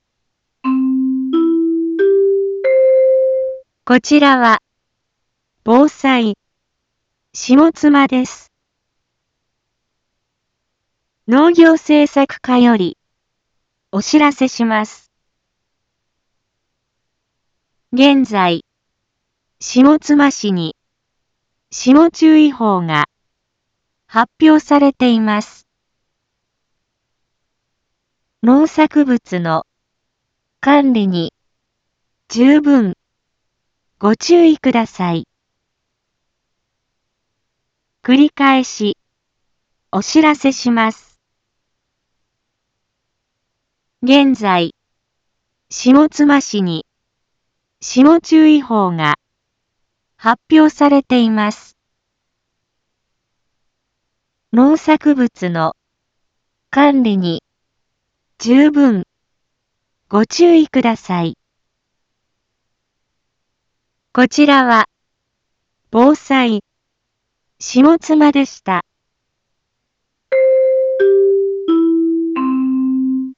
一般放送情報
Back Home 一般放送情報 音声放送 再生 一般放送情報 登録日時：2024-04-10 18:01:17 タイトル：霜注意報 インフォメーション：こちらは、防災、下妻です。